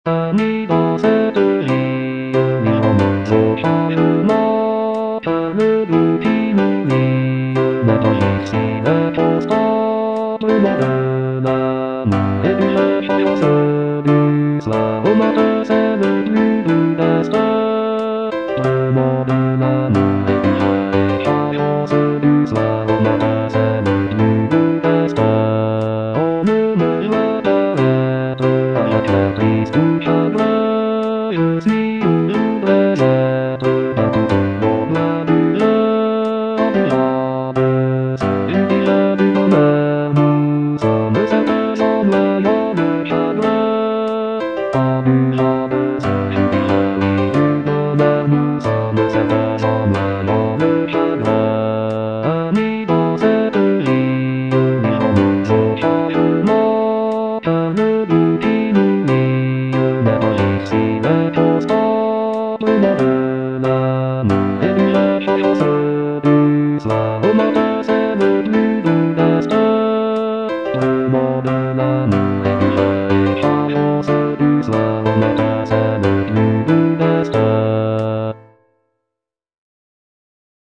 ANONYMOUS (ARR. R. DEMIEVILLE) - AMI, DANS CETTE VIE Bass (Voice with metronome) Ads stop: auto-stop
"Ami, dans cette vie" is a traditional French folk song arranged by R. Demieville. The piece features a beautiful melody accompanied by gentle harmonies, creating a soothing and heartfelt atmosphere.